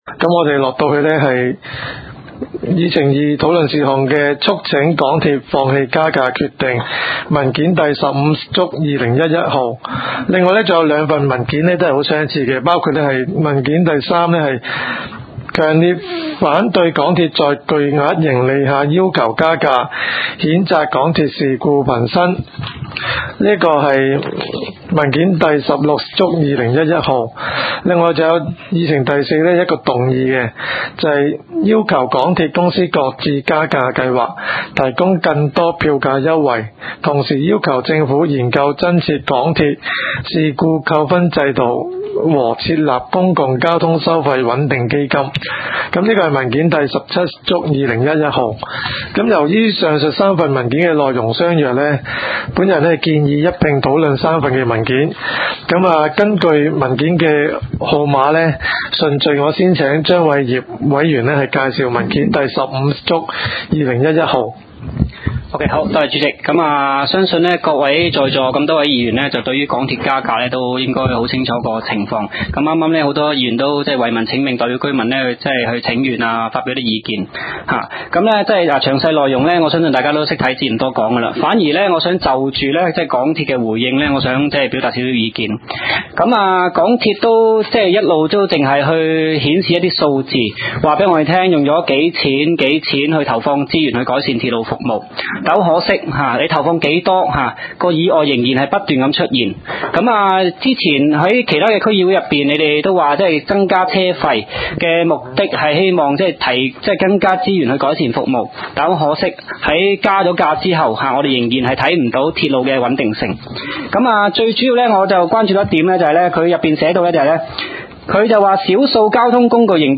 交通及運輸委員會第22次會議紀錄